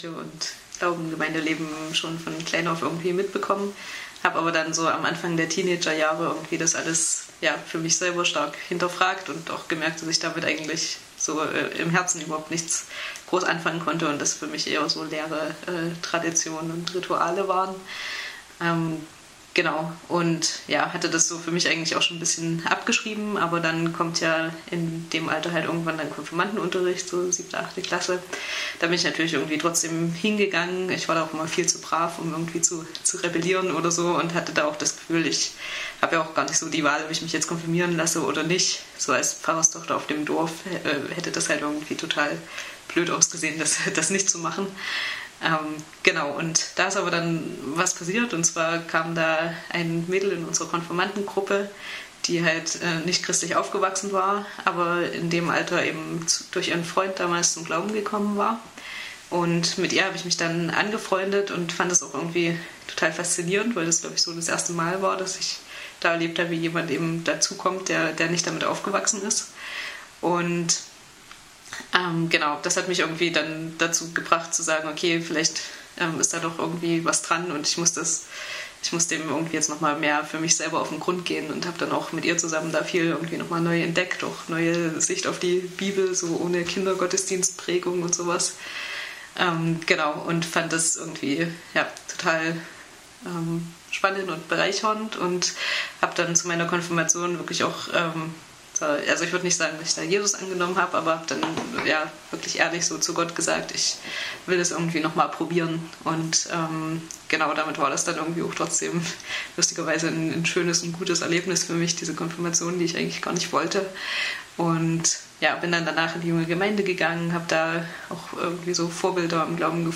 Diese Predigt gehört zur Predigtreihe: „über Jesus reden lernen“. Diesmal geht es darum, sich von Paulus inspirieren zu lassen und zu entdecken, was so schön daran ist, Christ zu sein.